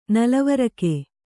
♪ nalavarake